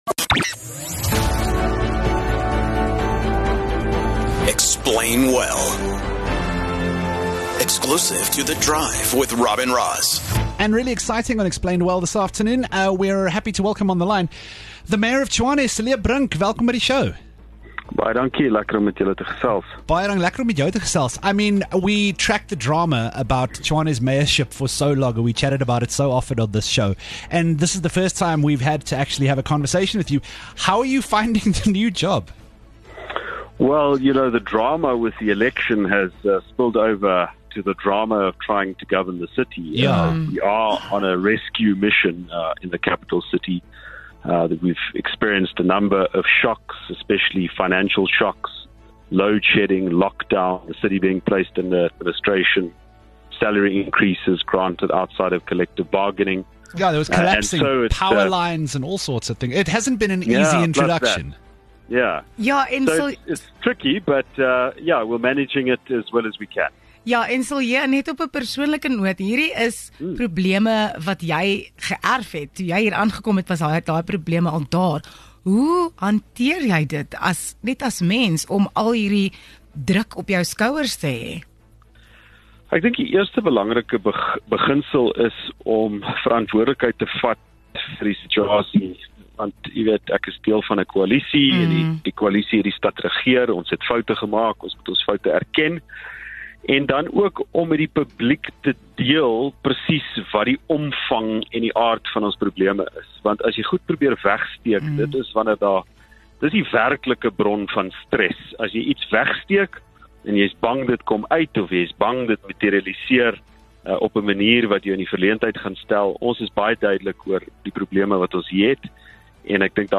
14 Aug Samwu-staking: Tshwane Burgemeester Cilliers Brink praat oor volgende stappe